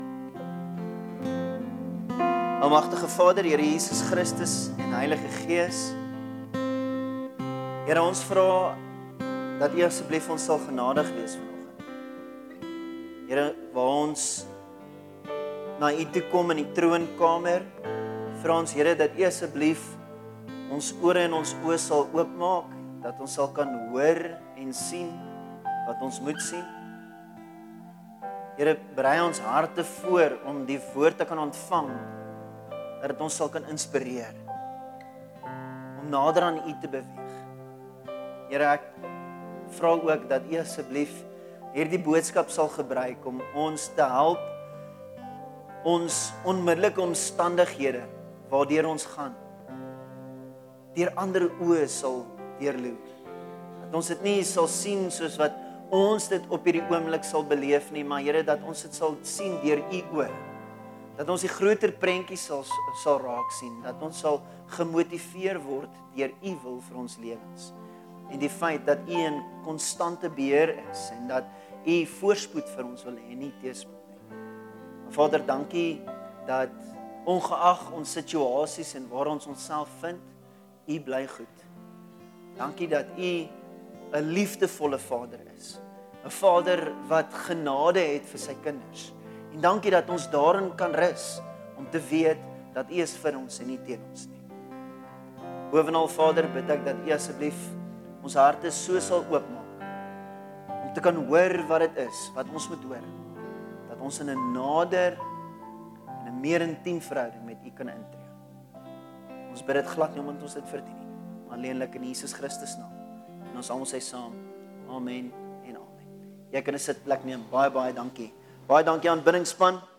Sermons Archive - Page 15 of 59 - KruisWeg Gemeente